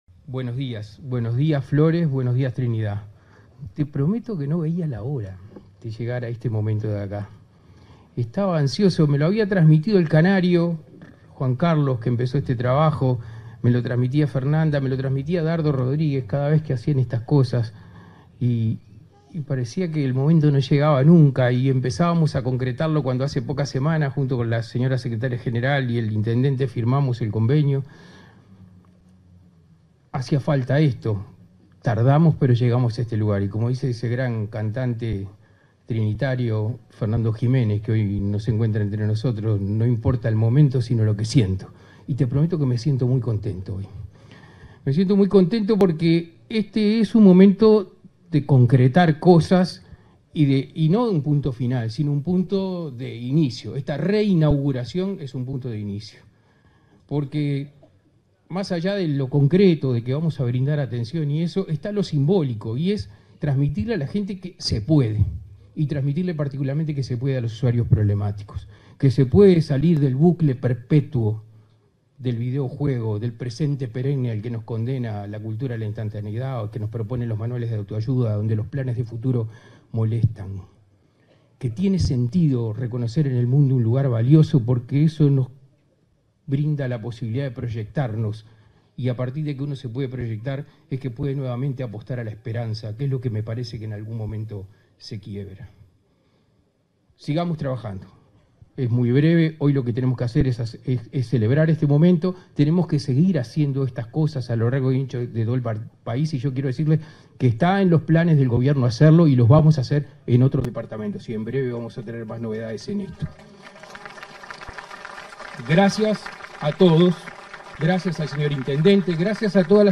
Palabras del secretario de la Junta Nacional de Drogas, Daniel Radío
En el marco de la inauguración de un Dispositivo Ciudadela de modalidad diurna en Flores, este 2 de junio, se expresó el secretario de la Junta